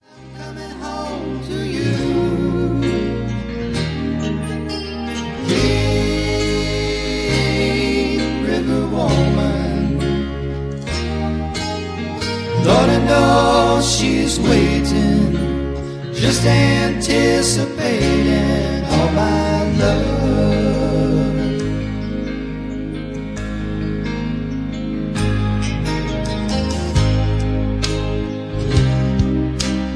(Key-Ab) Karaoke MP3 Backing Tracks
Just Plain & Simply "GREAT MUSIC" (No Lyrics).